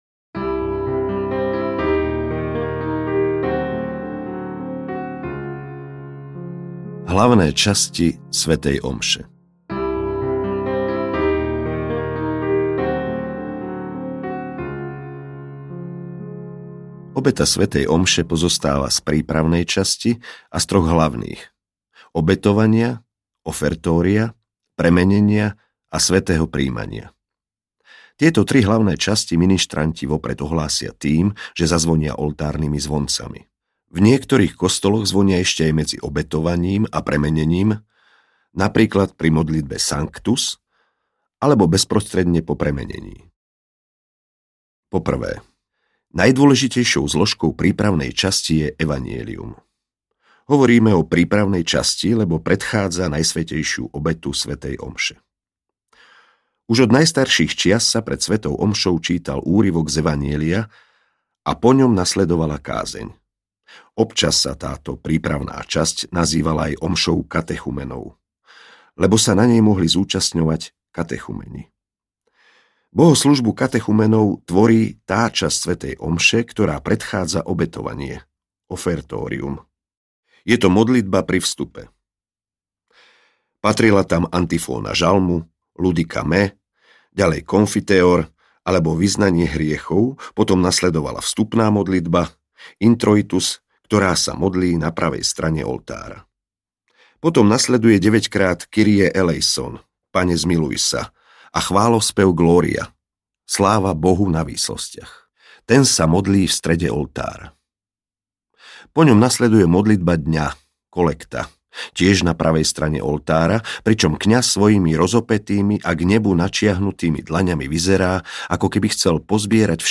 Zázrak svätej omše audiokniha
Ukázka z knihy